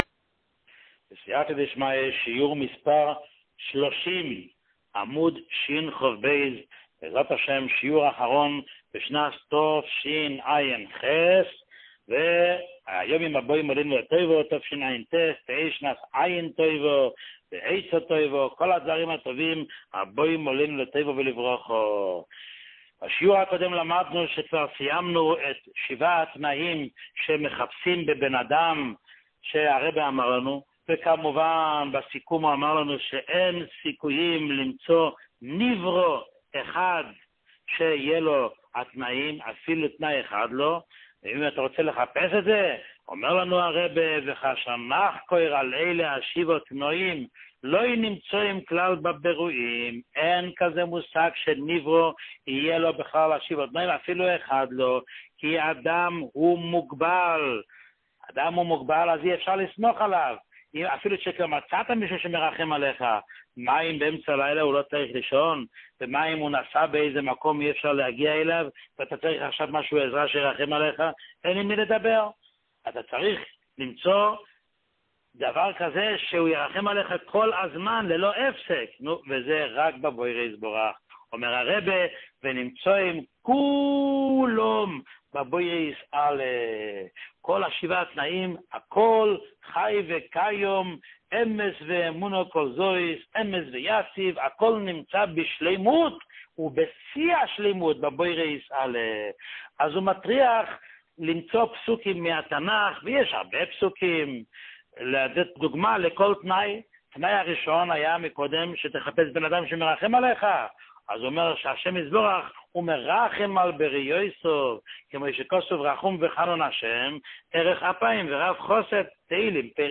שיעורים מיוחדים
שיעור 30